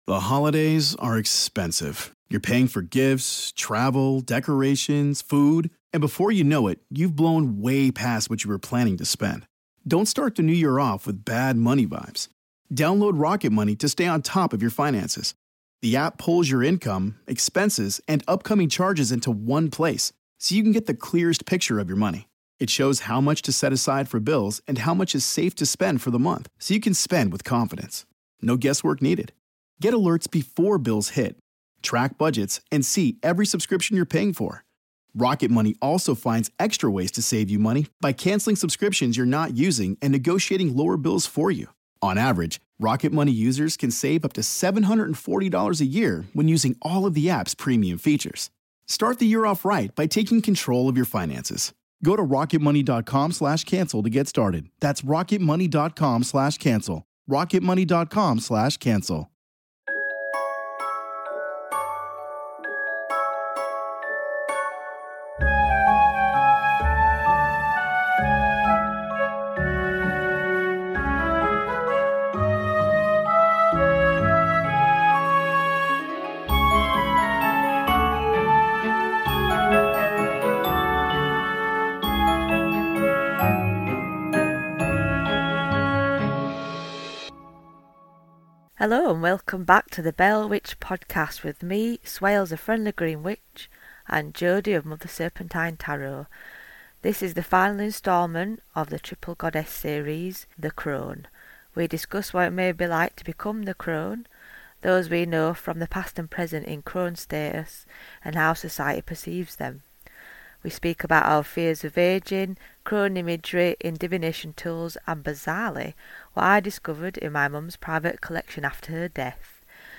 Pour yourself a brew or a glass of mead, come sit with us two northern birds and have a giggle as we go off on tangents. Expect laughter, soothing Yorkshire Mum sofa chat vibes.